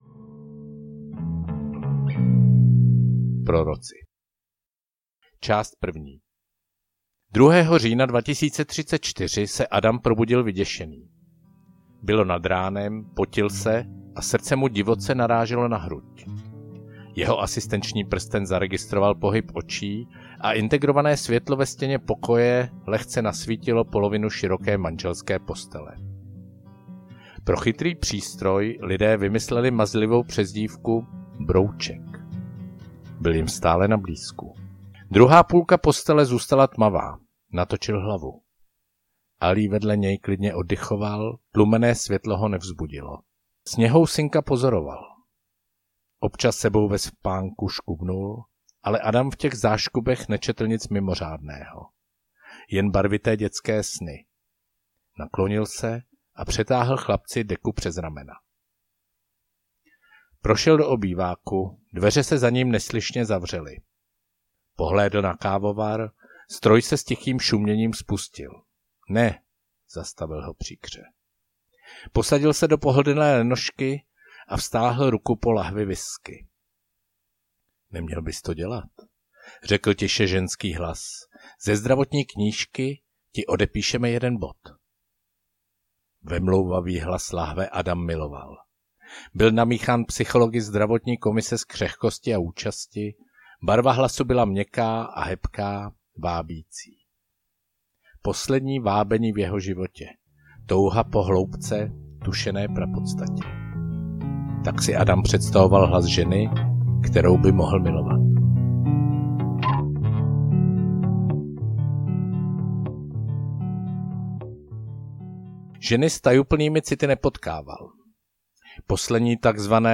Obejmi mě dočista, dočista… audiokniha
Ukázka z knihy